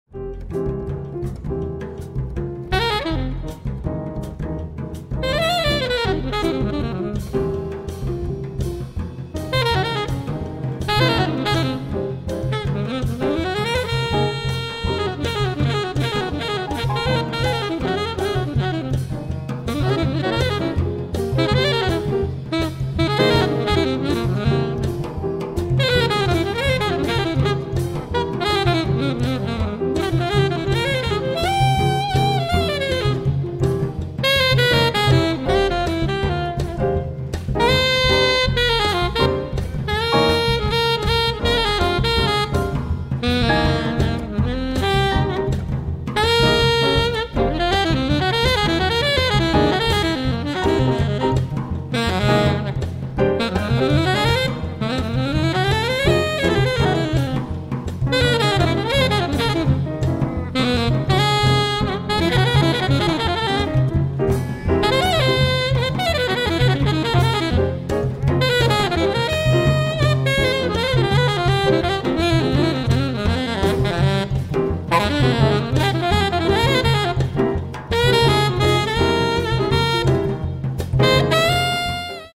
piano
bass
drums) lay down a deep groove on this F minor vamp
alto solo